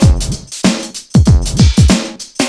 • 96 Bpm Drum Groove E Key.wav
Free drum loop - kick tuned to the E note. Loudest frequency: 1206Hz
96-bpm-drum-groove-e-key-1jS.wav